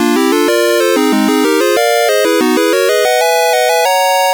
One of the jingles that plays at the start of a level
Source Recorded from the Sharp X1 version.